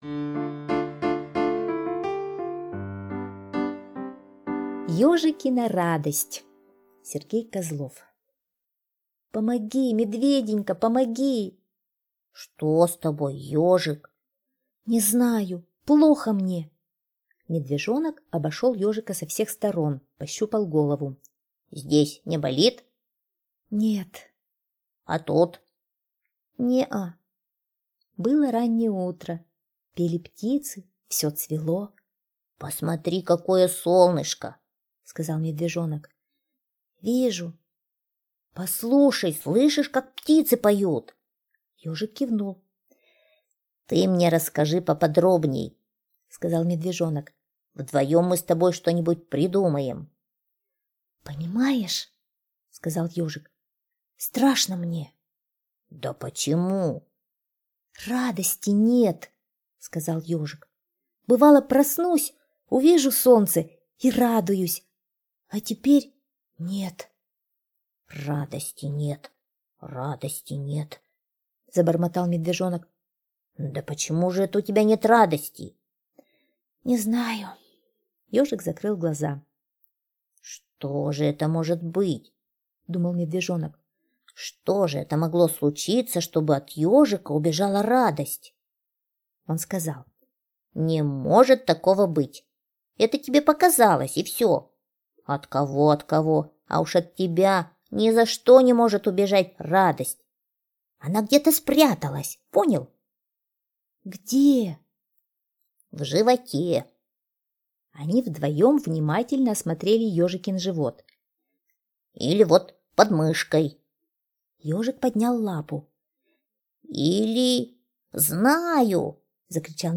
Слушайте Ежикина радость – аудиосказка Козлова С.Г. Сказка про Ежика, который потерял ощущение радости и Медвежонок помогал ему вернуть его.